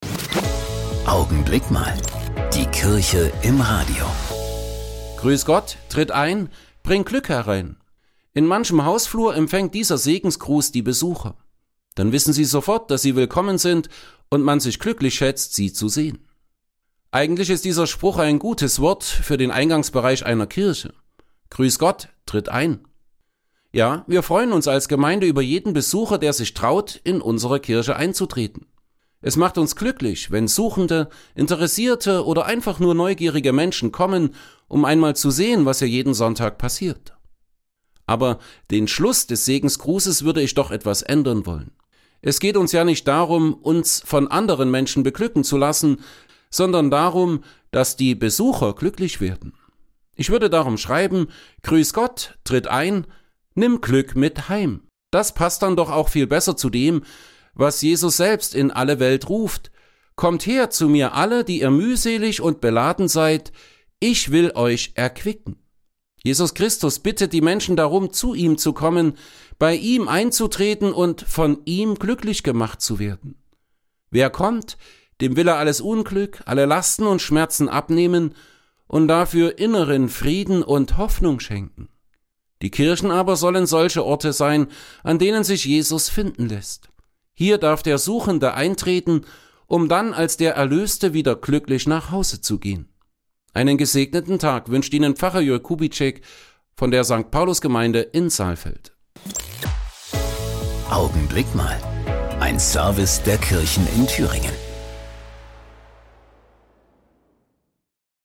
Radioandachten Gottesdienst